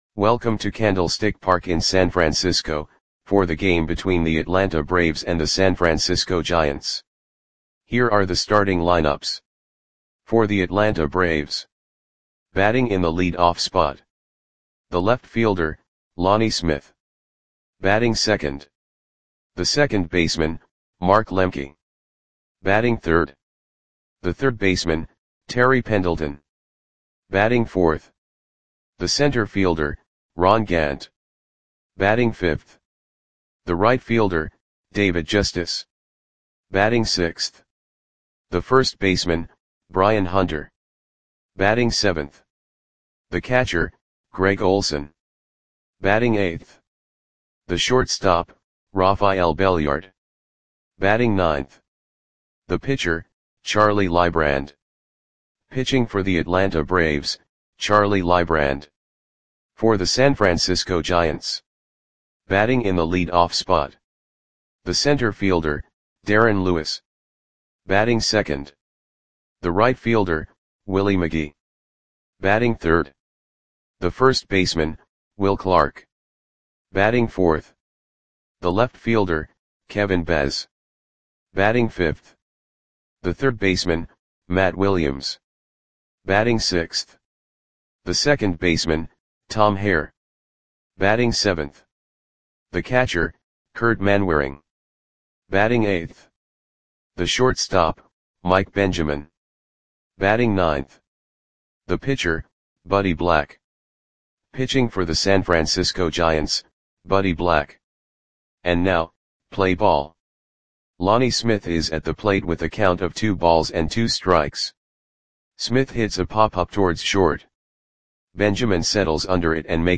Audio Play-by-Play for San Francisco Giants on September 17, 1991
Click the button below to listen to the audio play-by-play.